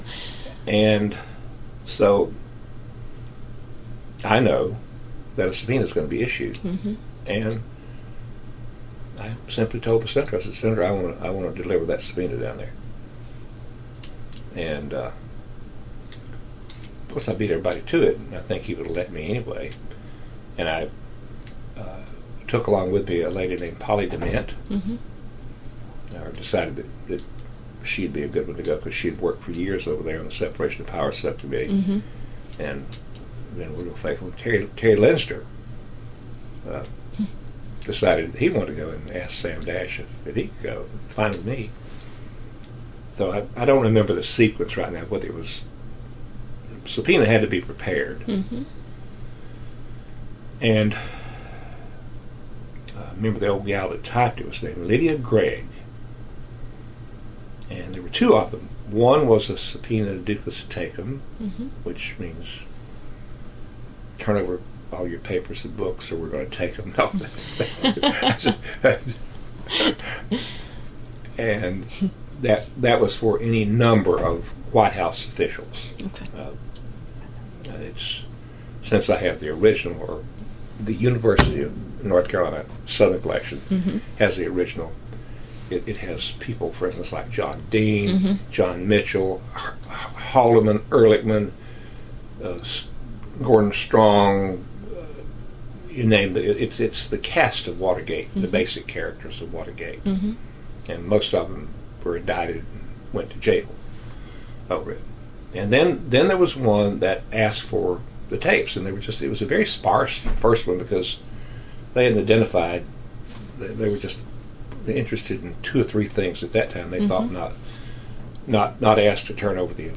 Oral History Excerpt | The Senate Watergate Committee Subpoenas White House Records
Rufus Edmisten, Deputy Chief Counsel, Senate Watergate Committee